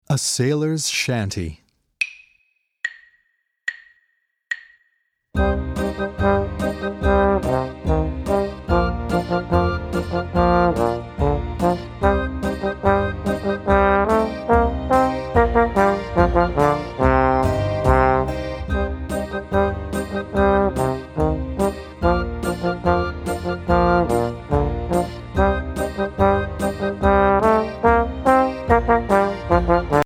Voicing: French Horn